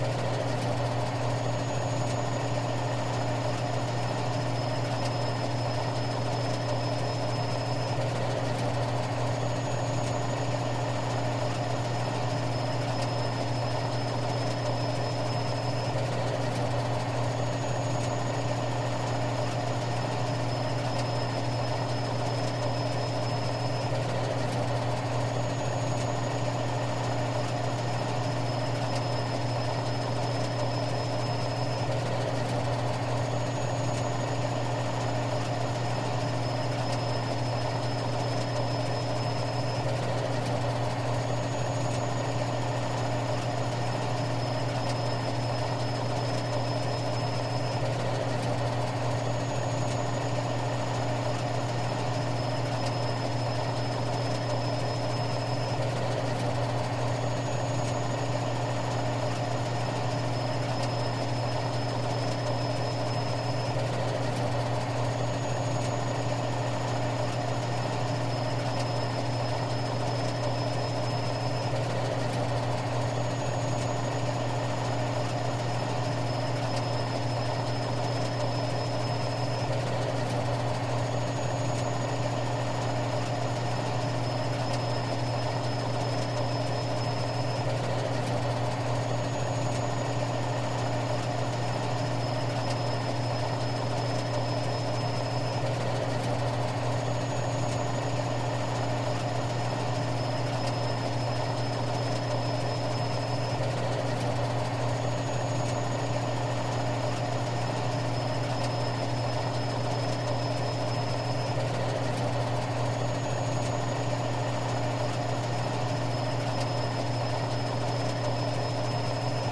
fuelfill.mp3